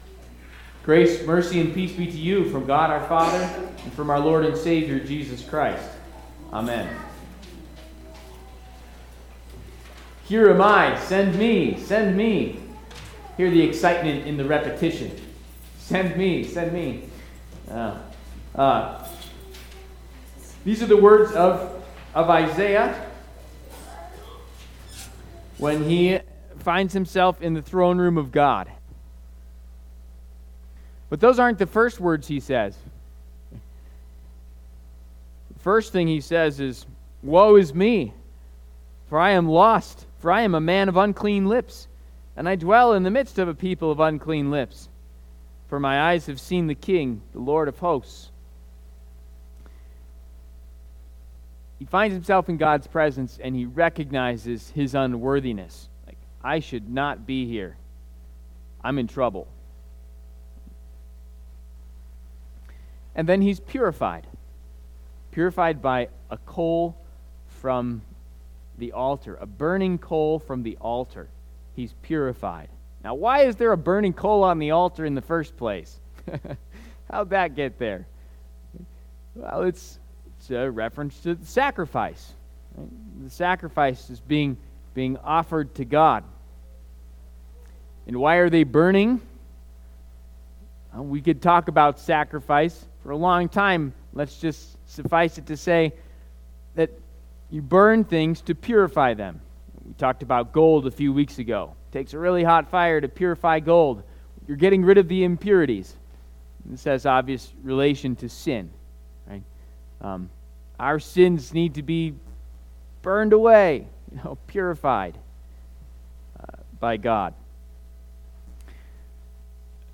Trinity Lutheran Church, Greeley, Colorado Purified and Risen in Christ Feb 09 2025 | 00:14:47 1x 00:00 / 00:14:47 Subscribe Share RSS Feed Share Link Embed